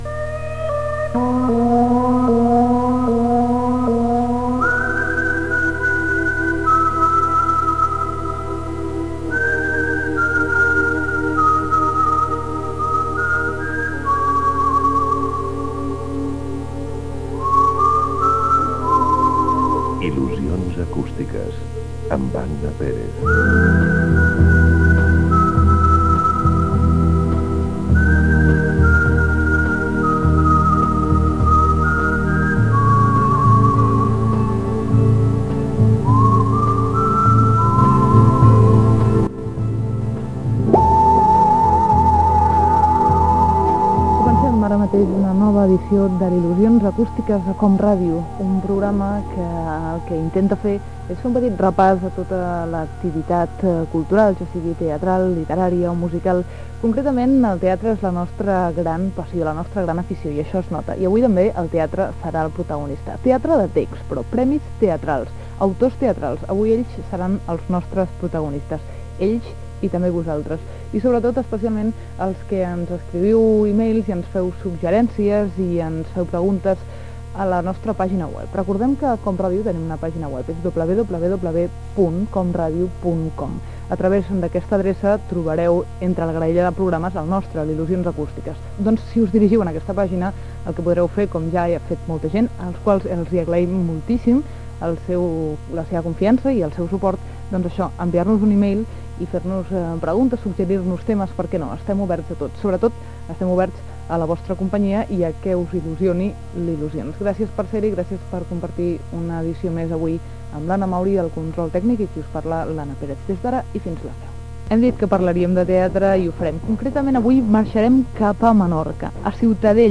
Careta i sumari del programa